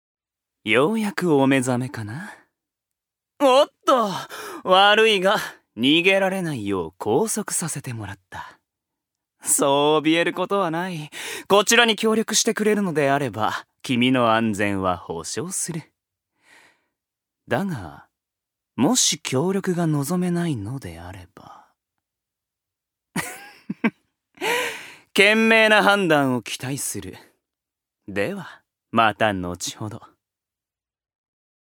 所属：男性タレント
音声サンプル
セリフ３